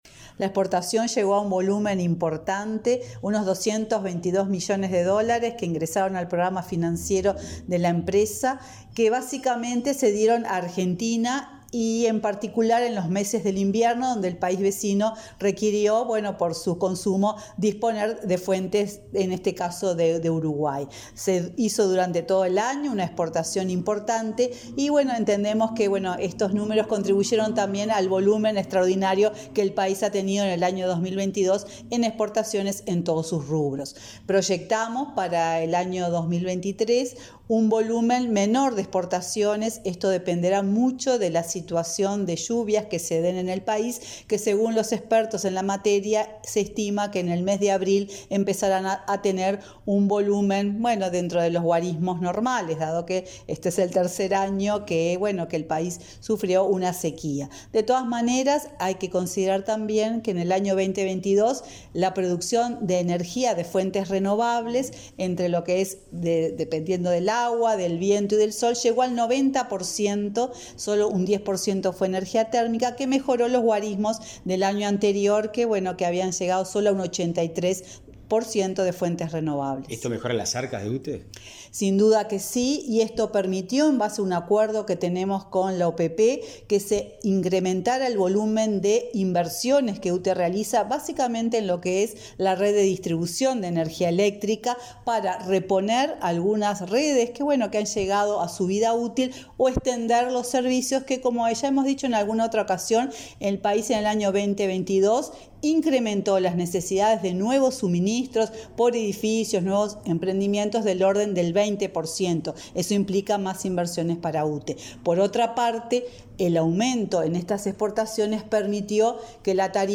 Entrevista a la presidenta de UTE, Silvia Emaldi